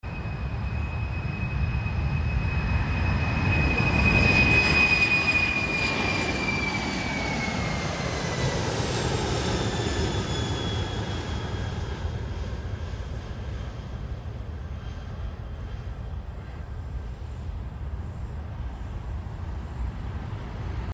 BOEING 747-47UF N476MC Atlas Air